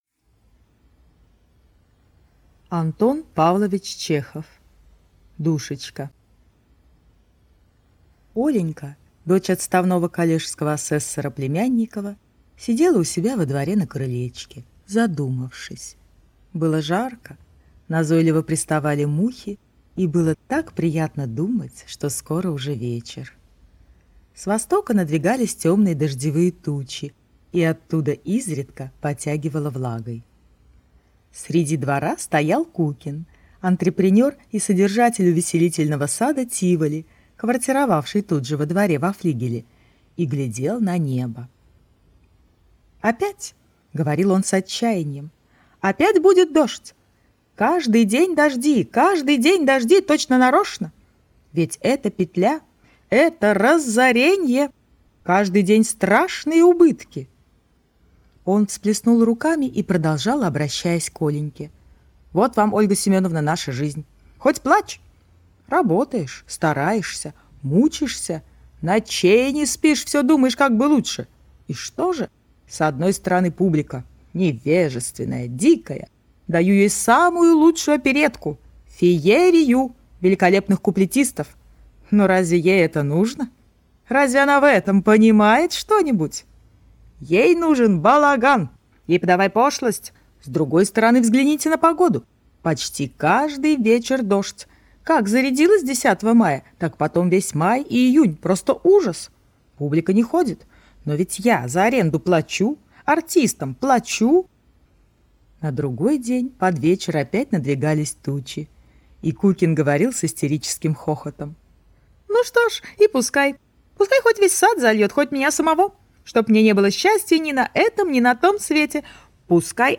Аудиокнига Душечка